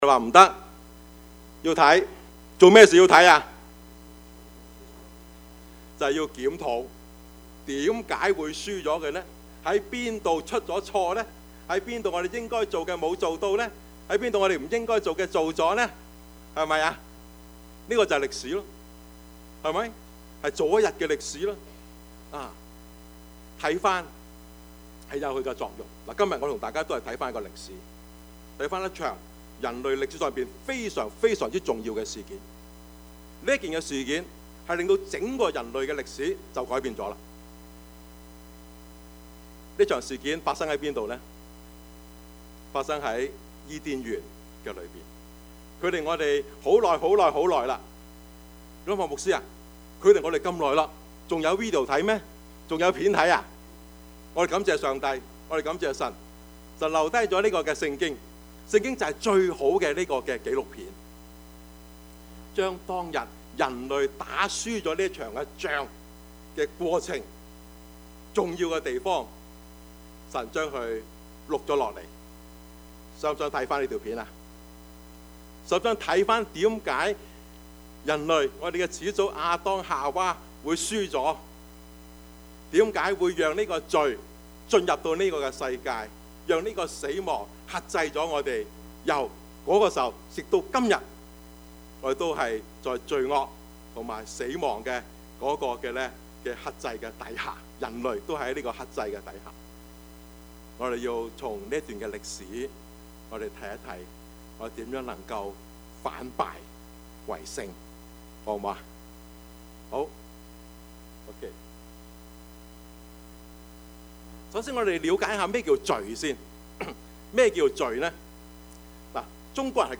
Service Type: 主日崇拜
Topics: 主日證道 « 人情味 匪兕匪虎 »